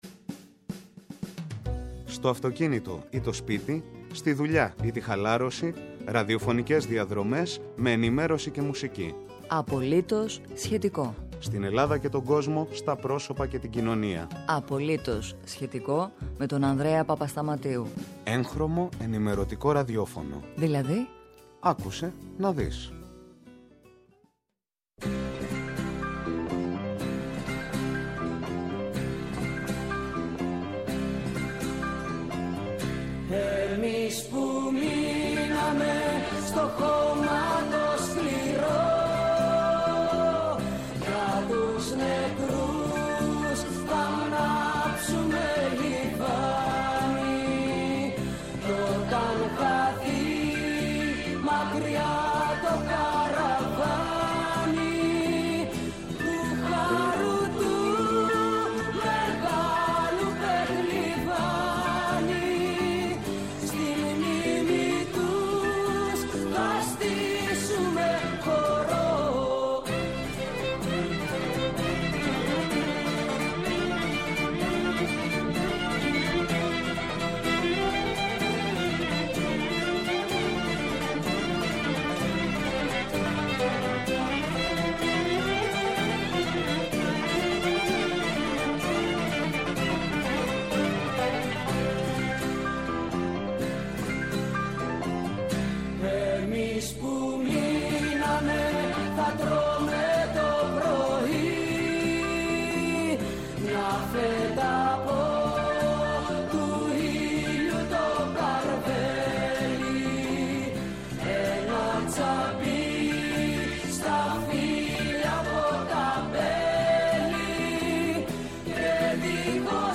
Καλεσμένος απόψε: Ο Γ.Γ. Φυσικού Περιβάλλοντος και Υδάτων του Υπουργείου Περιβάλλοντος και Ενέργειας Πέτρος Βαρελίδης Μιλάει για την παραπομπή της Ελλάδας στο Δικαστήριο της ΕΕ, επειδή δεν ολοκλήρωσε την αναθεώρηση των σχεδίων διαχείρισης λεκανών απορροής ποταμών και των σχεδίων διαχείρισης κινδύνων πλημμύρας.